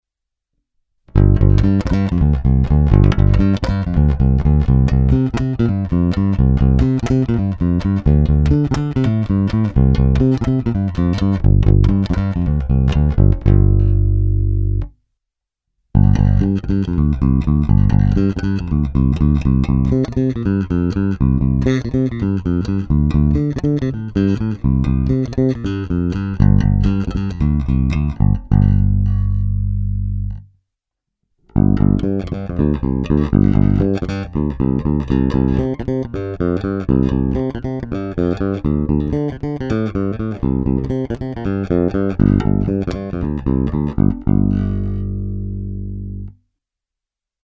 Není-li uvedeno jinak, následující nahrávky jsou vyvedeny rovnou do zvukové karty a vždy s plně otevřenou tónovou clonou a s korekcemi v nulové poloze, následně jsou jen normalizovány, jinak ponechány bez úprav.
Ukázka 2 ve stejném pořadí jako výše